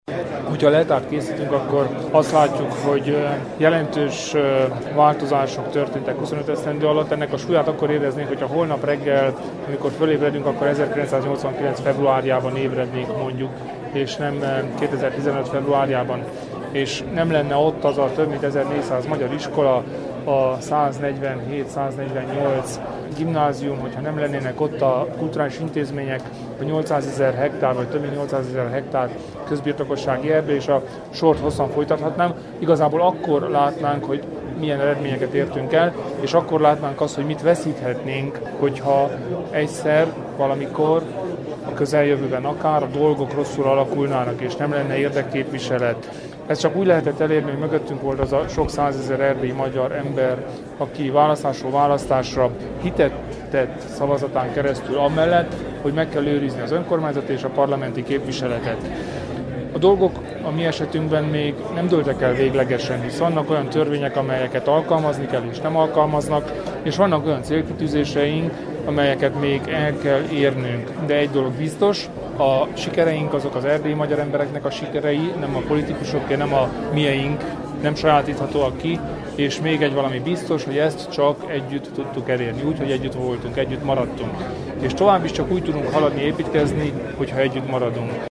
A kolozsvári magyar operában tartott gálán ünnepelte megalapítása 25. évfordulóját a Romániai Magyar Demokrata Szövetség (RMDSZ).
Hallgassa meg Kelemen Hunor nyilatkozatát!
kelemen_hunor_rmdsz_25_gala.mp3